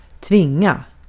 Uttal
Synonymer få förplikta nödga Uttal : IPA: /ˈtvɪŋˌa/ Ordet hittades på dessa språk: svenska Ingen översättning hittades i den valda målspråket.